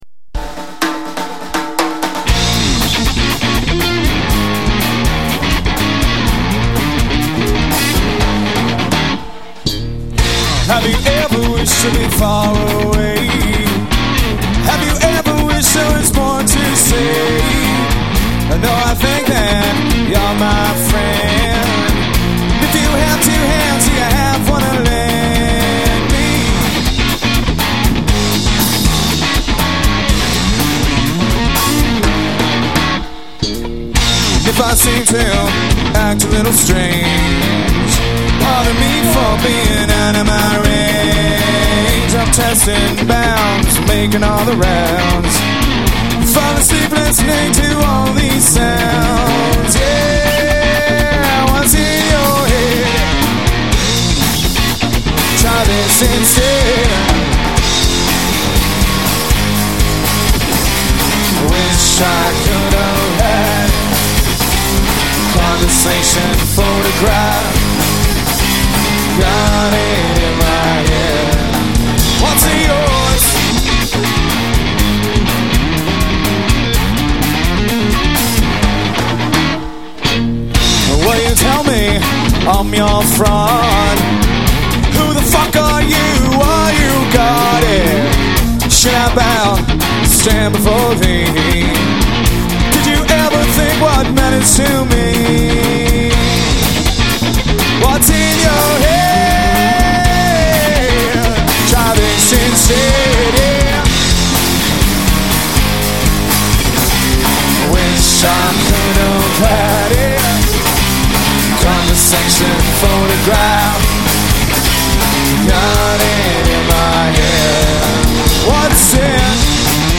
These songs are taken from a live show in Whitehorse.